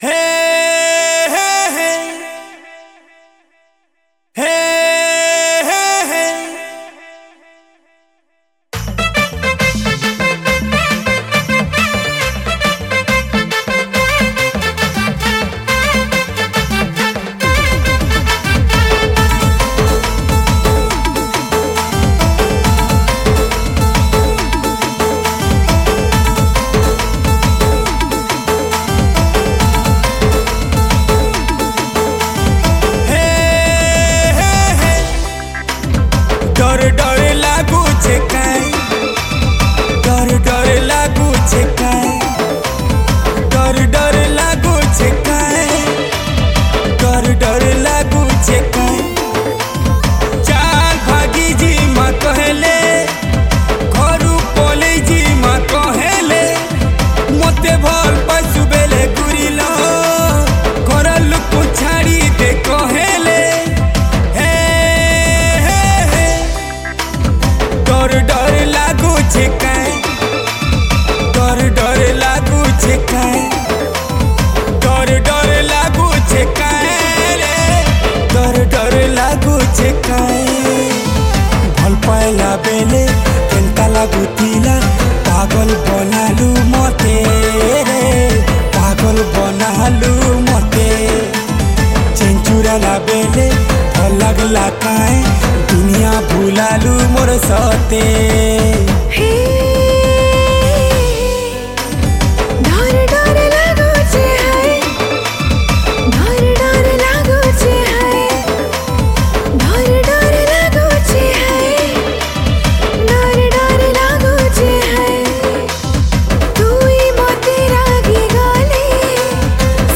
Category: New Sambalpuri